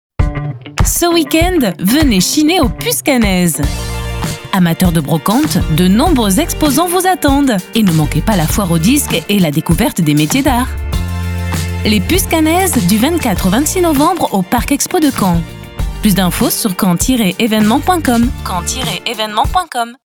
SPOT RADIO PUCES CAENNAISES NOV2023